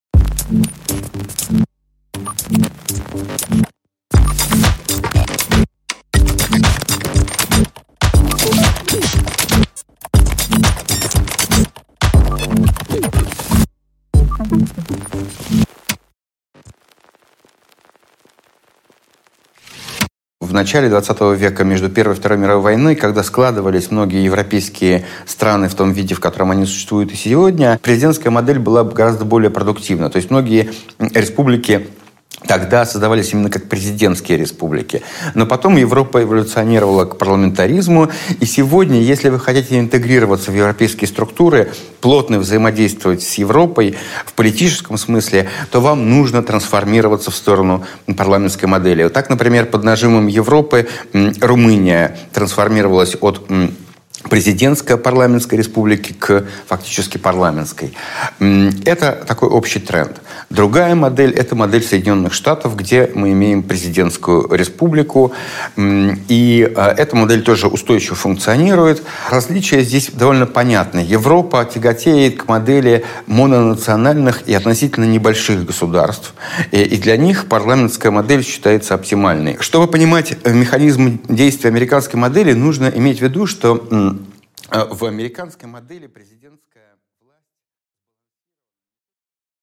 Аудиокнига Вернуть парламент!